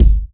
DJP_KICK_ (49).wav